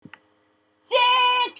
funny voices